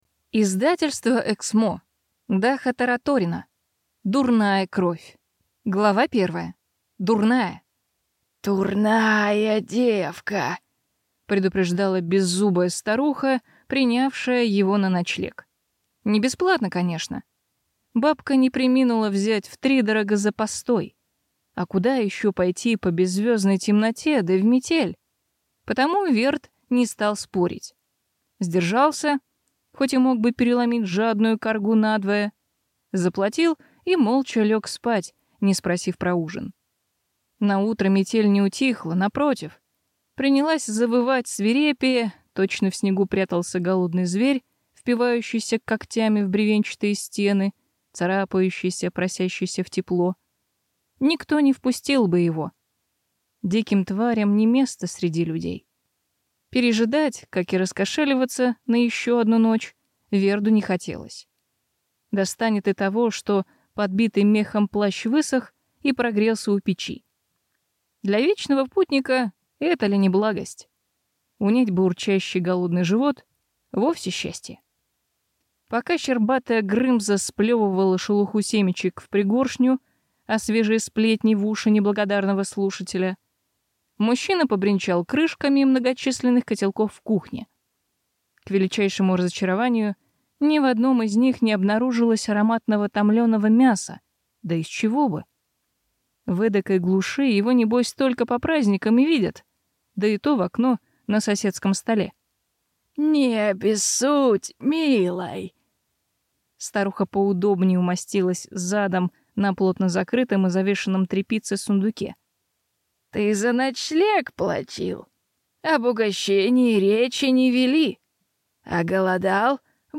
Аудиокнига Дурная кровь | Библиотека аудиокниг
Прослушать и бесплатно скачать фрагмент аудиокниги